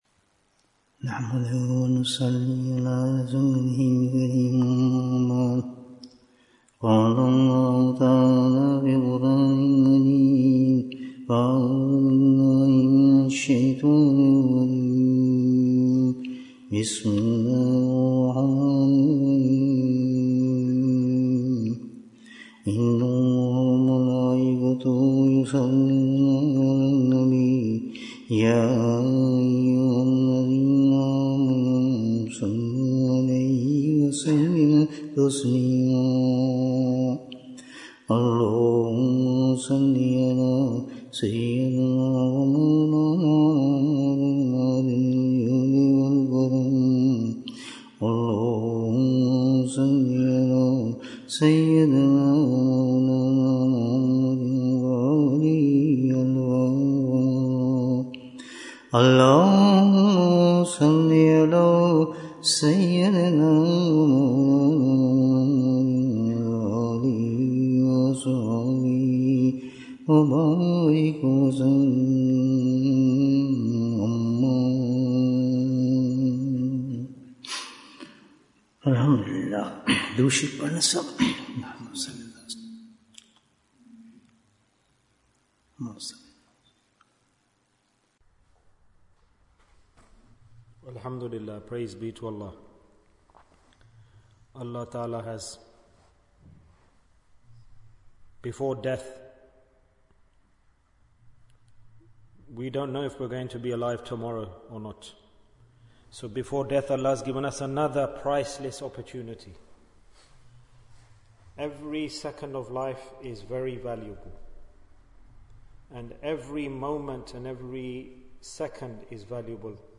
How to Fulfil the Right of Servitude? Bayan, 44 minutes29th August, 2024